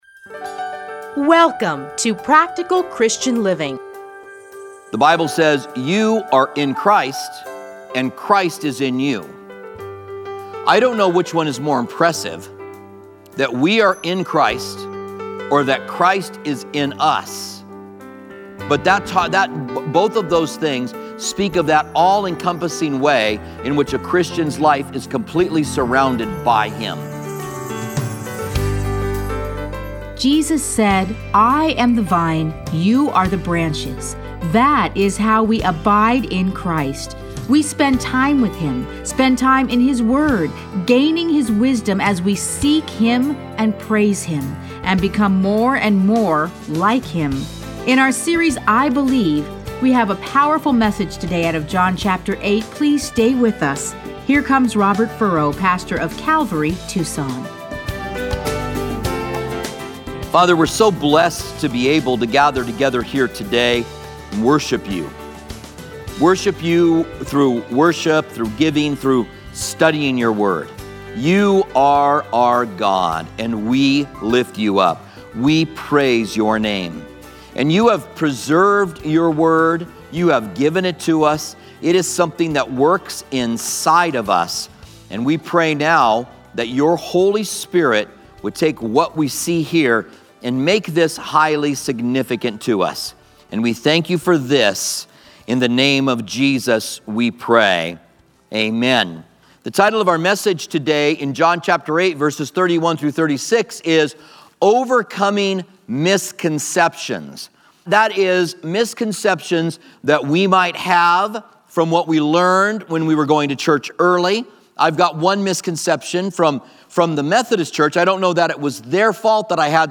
Listen to a teaching from John 8:31-36.